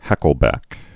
(hăkəl-băk)